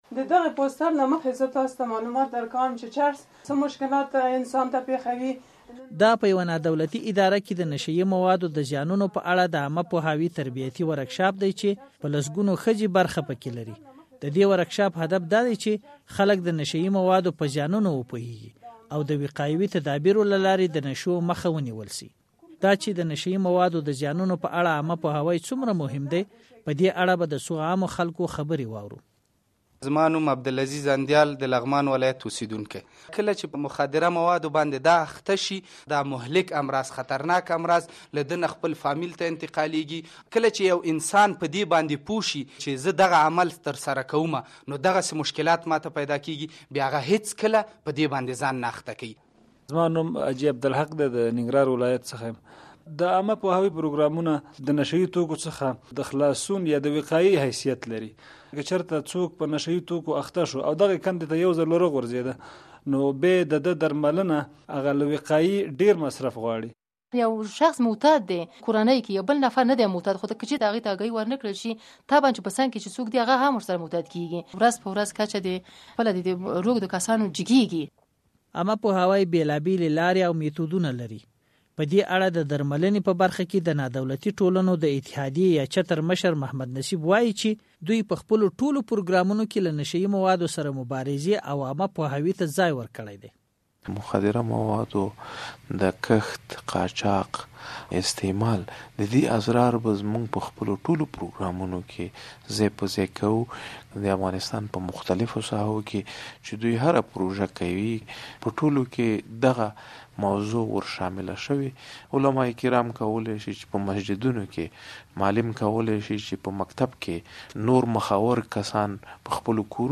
د زهرو کاروان فیچر
دا چې د نشه‌يي موادو د زیانونو په اړه عامه پوهاوی څومره مهم دی، په دې اړه به د څو عامو خلکو خبرې واورو: